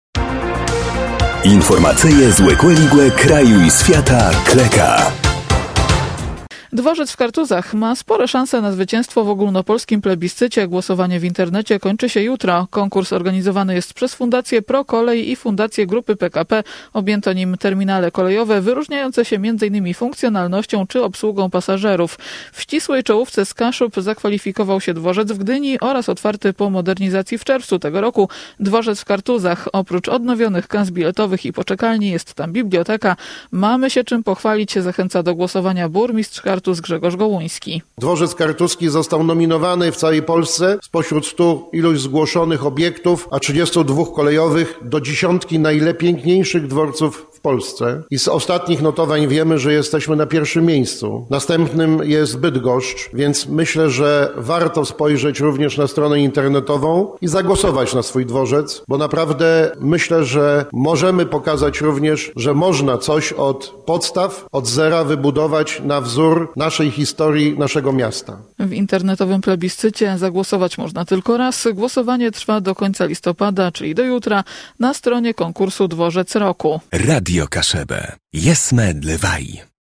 – Mamy się czym pochwalić – zachęca do głosowania burmistrz Kartuz Grzegorz Gołuński.